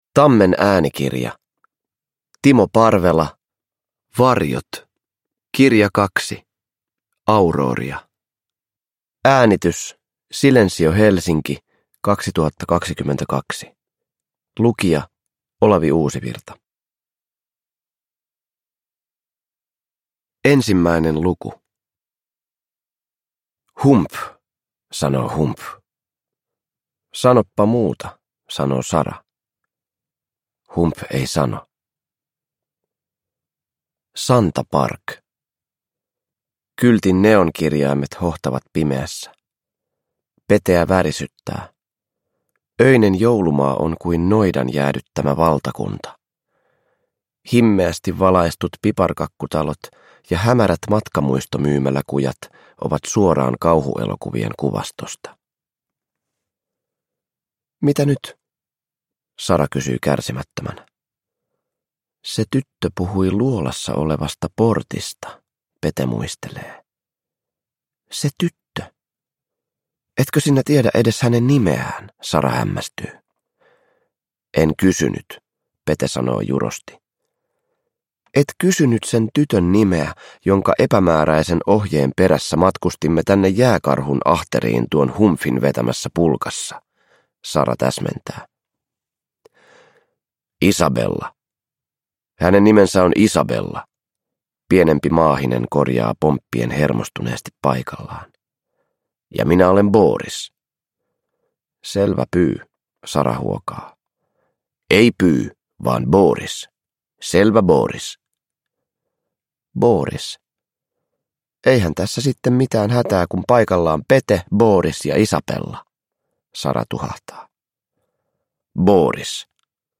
Uppläsare: Olavi Uusivirta